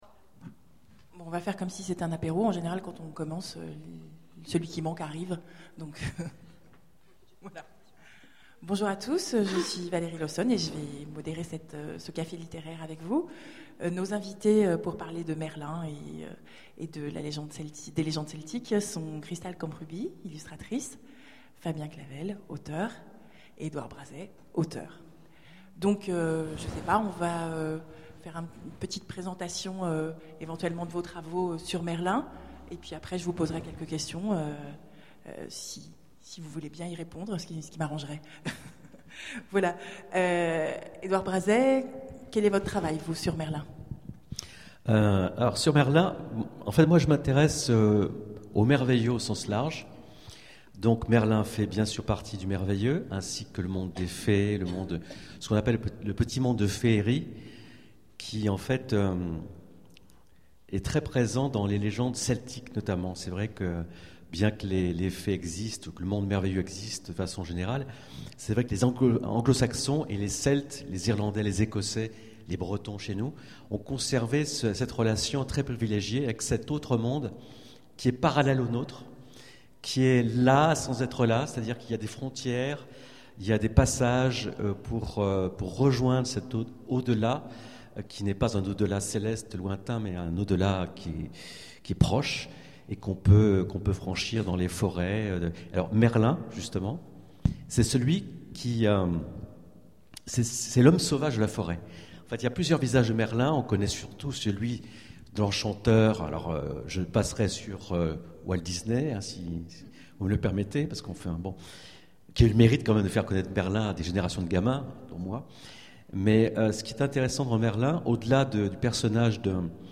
Imaginales 2011 : Conférence Merlin l'enchanteur et les chevaliers de la table ronde